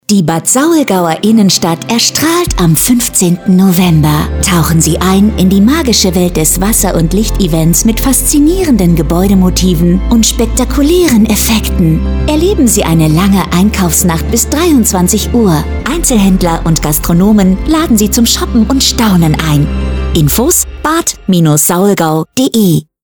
Radiospot Bad Saulgau leuchtet